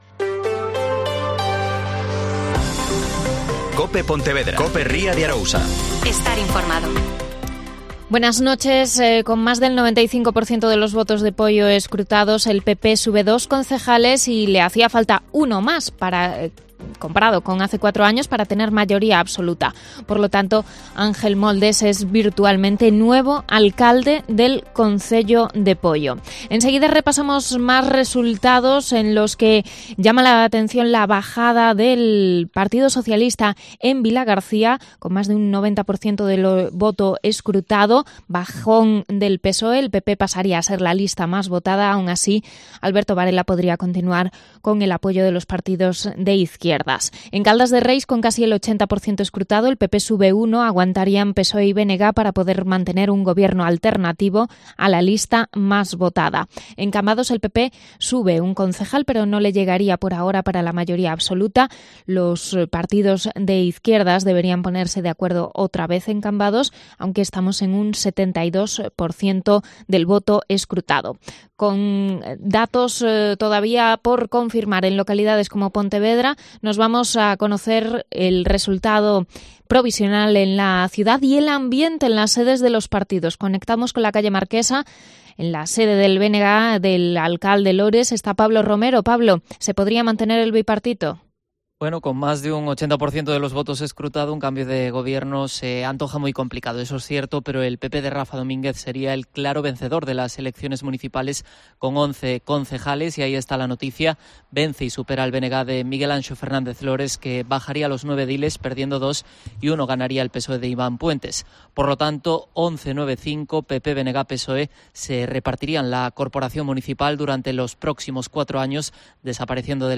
Especial Elecciones Municipales 2023 (Informativo 22,23h)